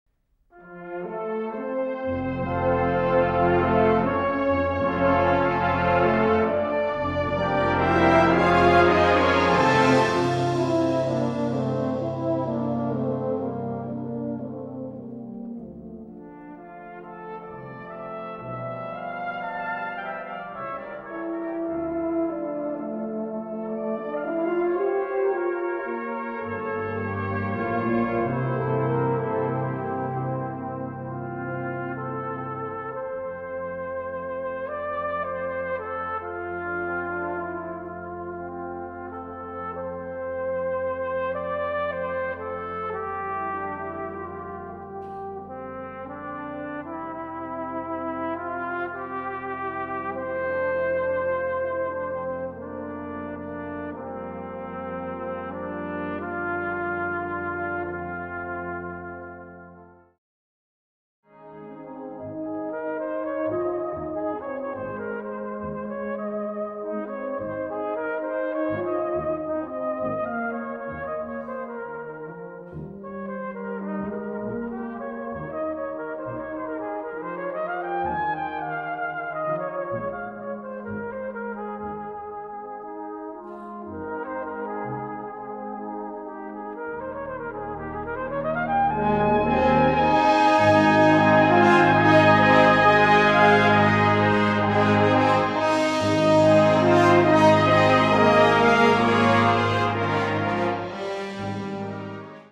Music for brass ensemble.
performance sound sample (brass band version